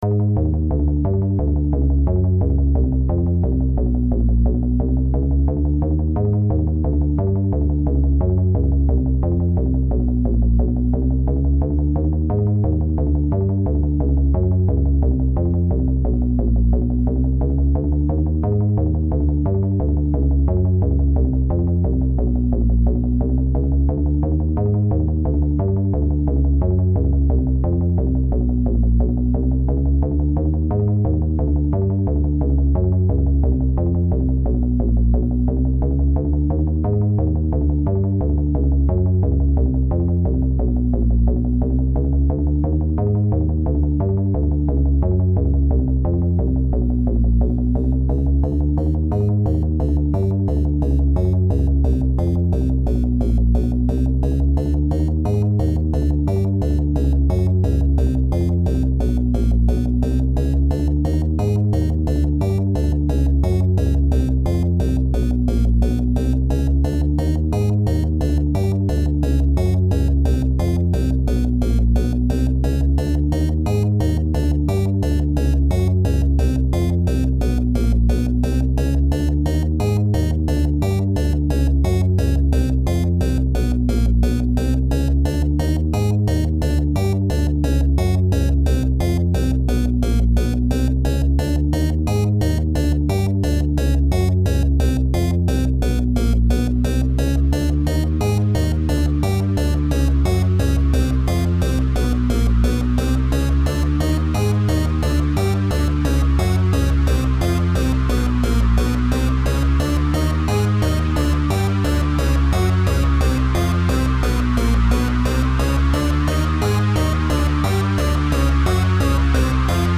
【用途/イメージ】　怪談　都市伝説　ダーク　不気味　不思議　緊張
シンセベース　ノイズ